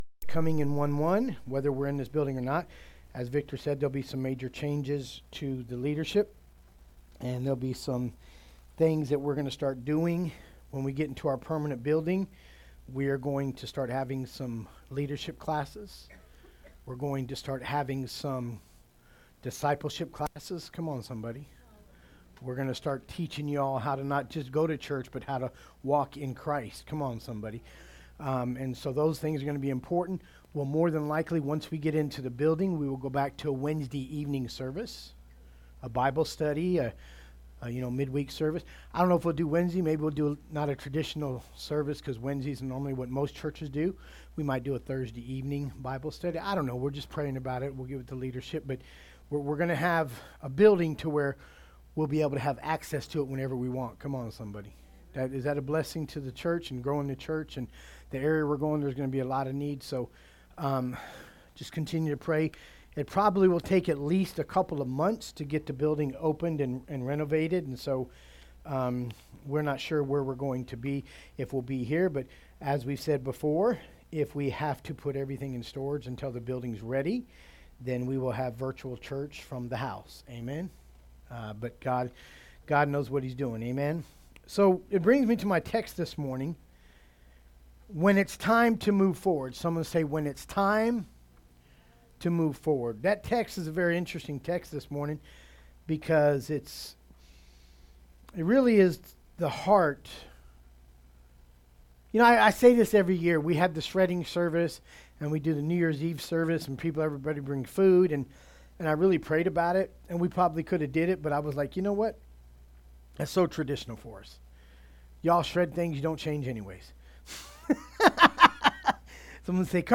Passage: Deuteronomy 1:6 - 8 Service Type: Sunday Service « He Is Worthy Of Our Praise.